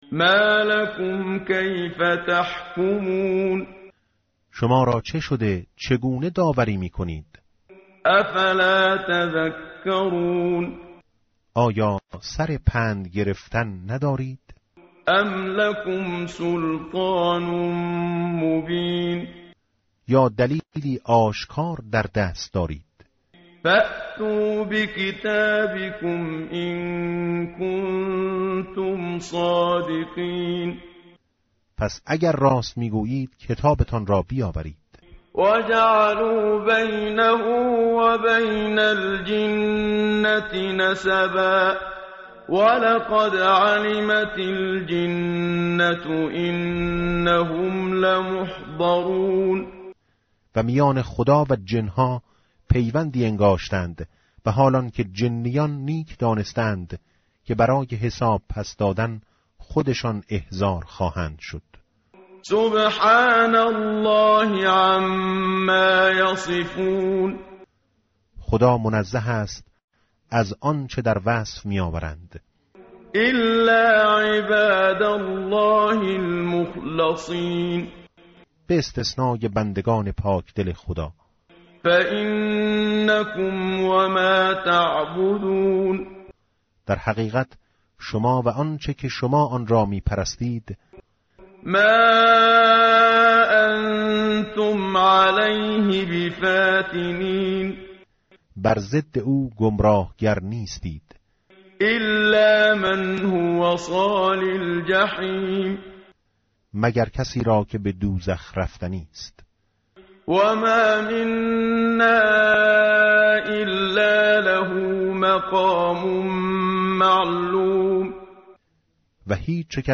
متن قرآن همراه باتلاوت قرآن و ترجمه
tartil_menshavi va tarjome_Page_452.mp3